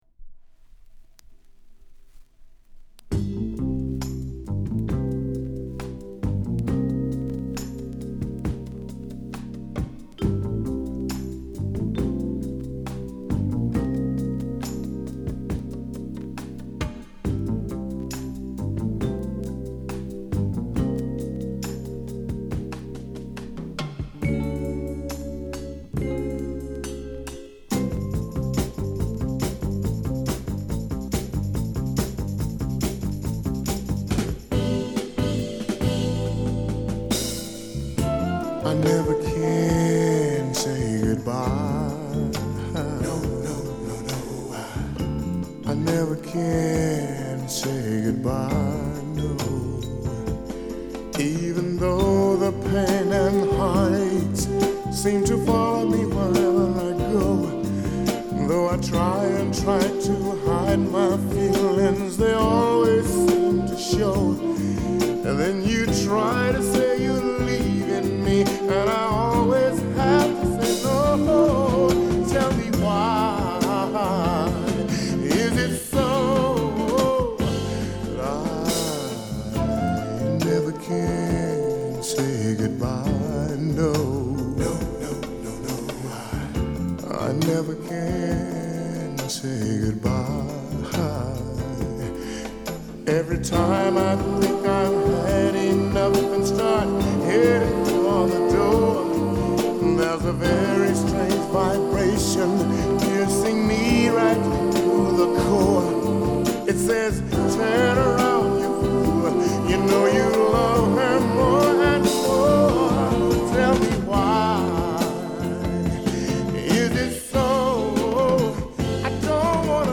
独特のヘヴィーボイスでソウルフルに唄い上げたニューソウル名作！